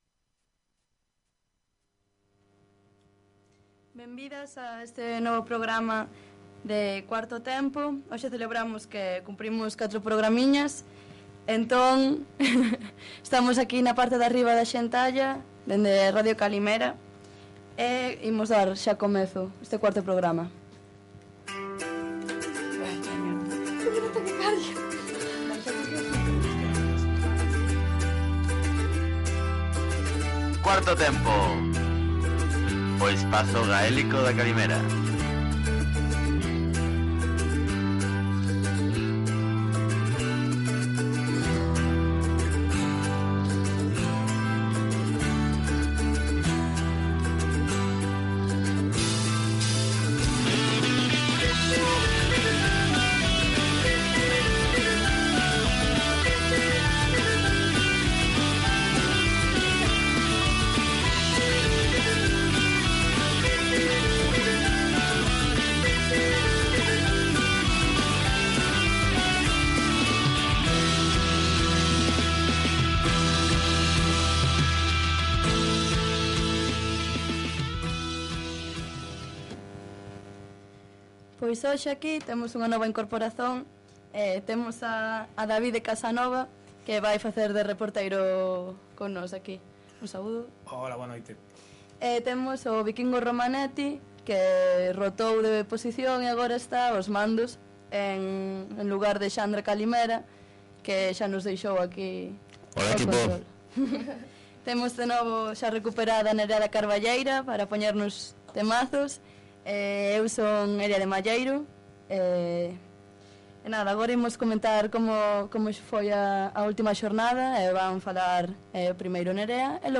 Emitido o dia 8/X/15 na rádio Kalimera (A rádio livre de Compostela). Falaremos da história do futebol gaélico, a Liga Gallaecia, algumha dica básica de como se joga, comentara-se a última jornada e a classificaçom geral assim como o conselho para sobreviver ao terceiro tempo. Todo isto amenizado com a melhor música galaico/gaélica.